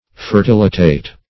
\Fer*til"i*tate\